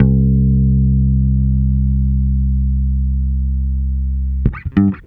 Track 13 - Bass 01.wav